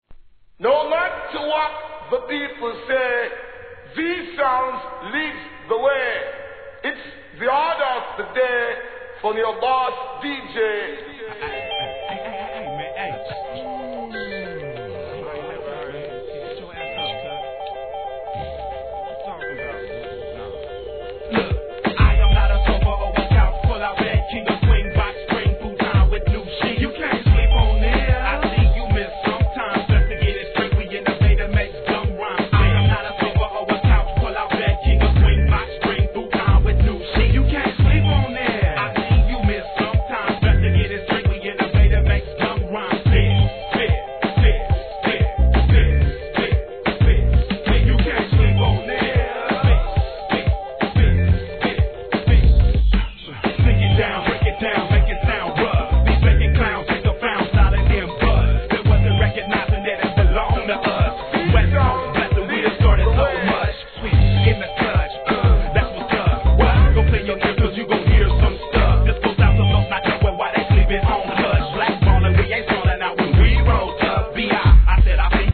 HIP HOP/R&B
西海岸の良質アンダーグラウン!!